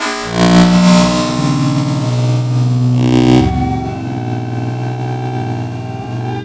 UNDERSEA  -R.wav